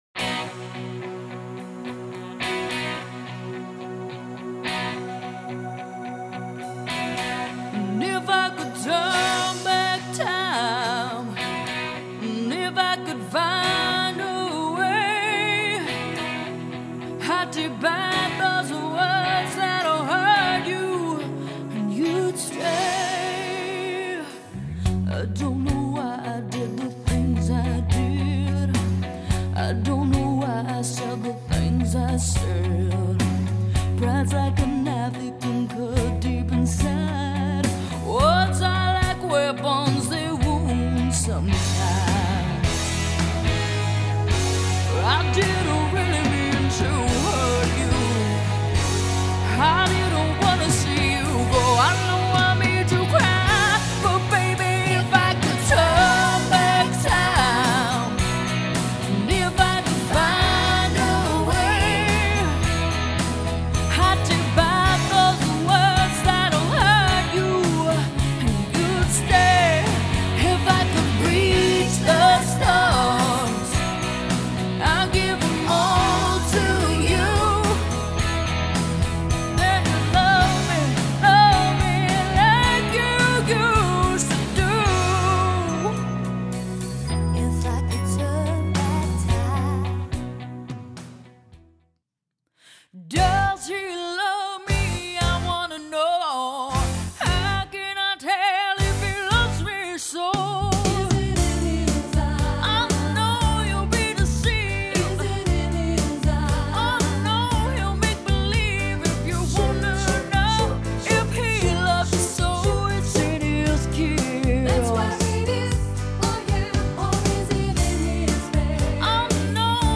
tribute act to the Californian legend
alto and soprano